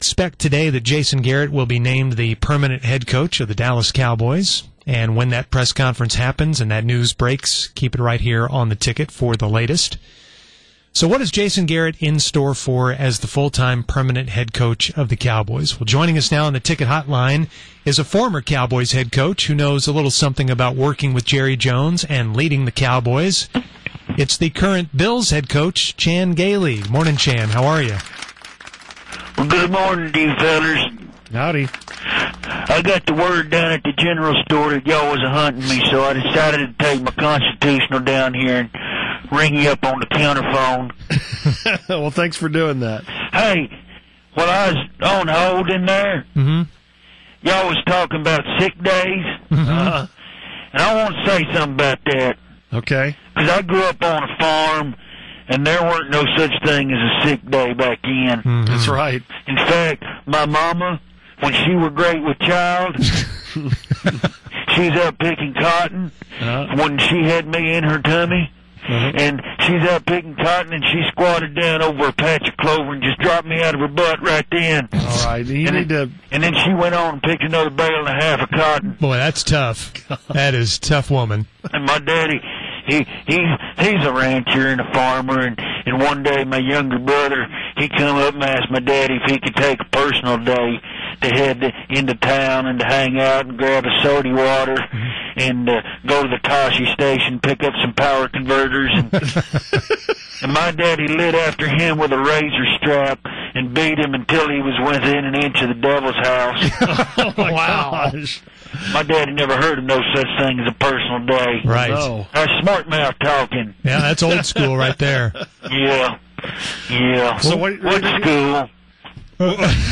Fake Chan Gailey - Garrett As Cowboys Coach - The UnTicket